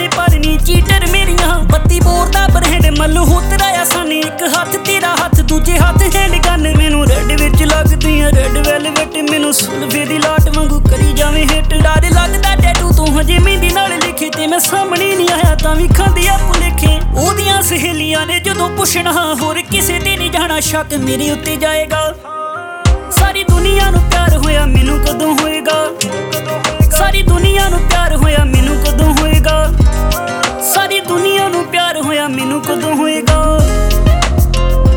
Жанр: Инди / Местная инди-музыка